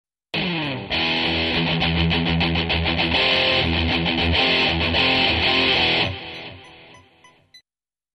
Si ca vous interesse, je vous mettrai un petit enregistrement de gratte avec ce micro dés que je trouve un cable.
Uni-Directional Dynamic Microphone
Je l'ai placé à 6 cm de la membrane de l'ampli. Je n'ai pas corrigé le son à la sortie du micro.